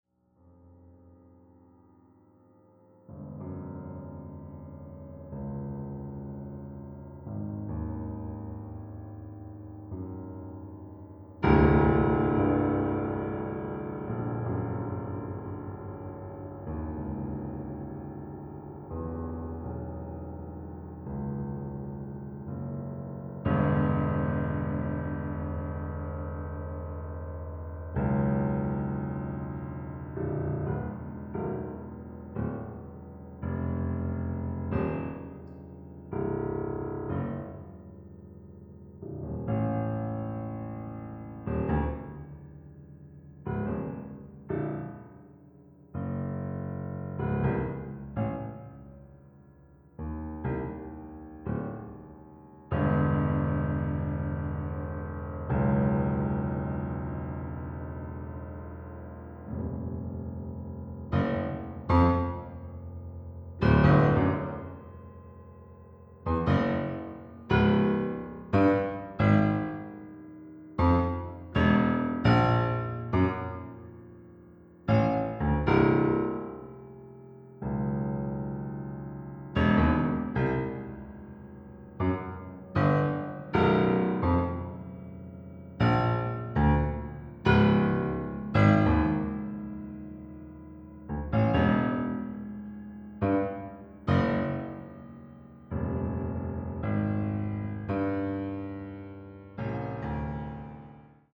Australian classical music
an hour-long cycle for solo piano